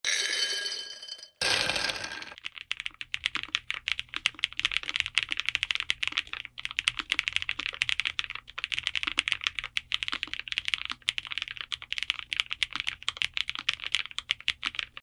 Lofree x Kailh Phantom Low-Profile Switch Sound Tests using the Lofree Flow84 mechanical keyboard with both Lofree Dye-Sub PBT and XVX Double Shot PBT low-profile keycap sets. The Lofree Phantom is a Tactile Low-Profile switch.